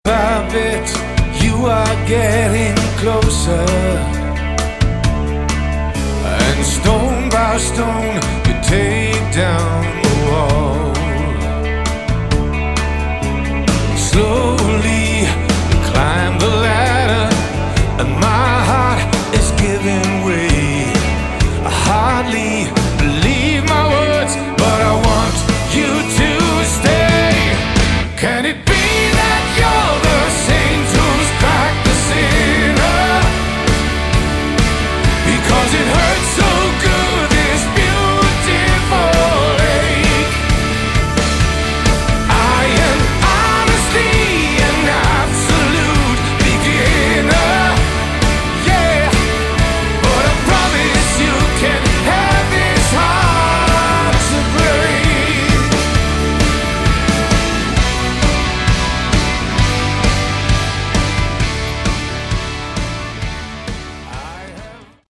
Category: AOR / Melodic Rock
Vocals & Guitar
Keyboards
Drums
Bass